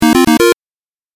レトロゲーム （105件）
8bit勝利3.mp3